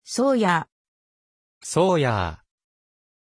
Aussprache von Sawyer
pronunciation-sawyer-ja.mp3